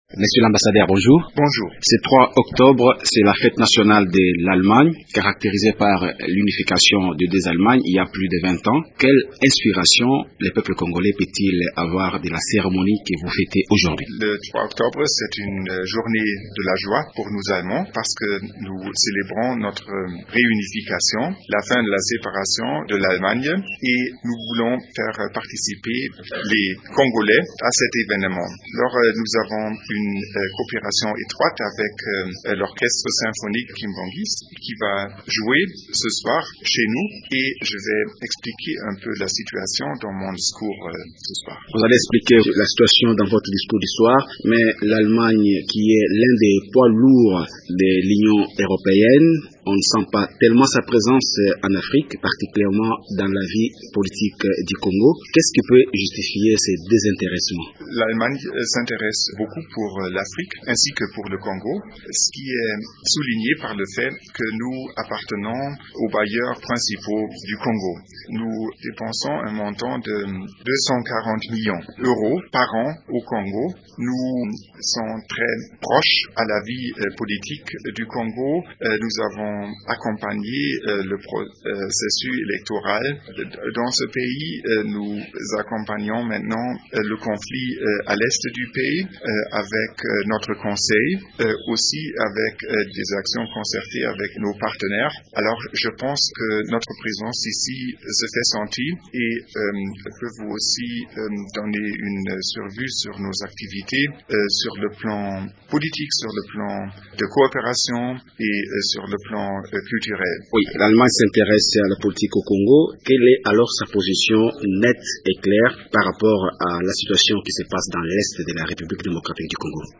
Peter Blomeyer est interrogé